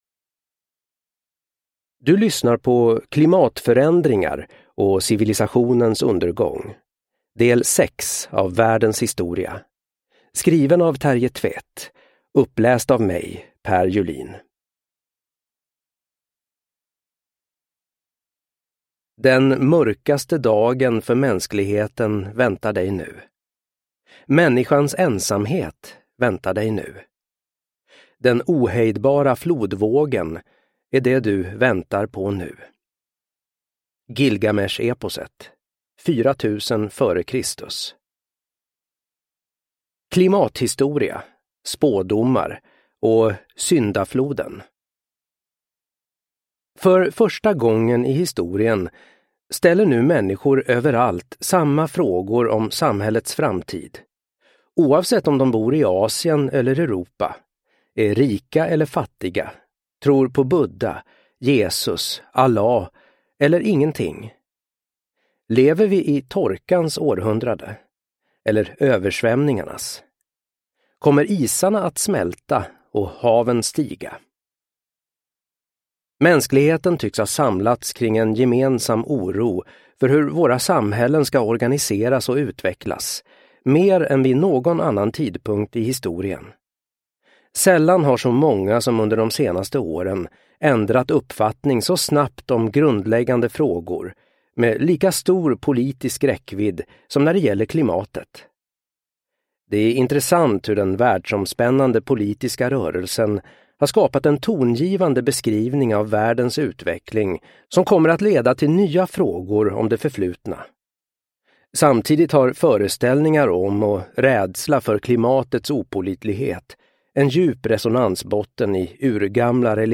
Världens historia: Del 6 – Klimatförändringar och civilisationers undergång – Ljudbok – Laddas ner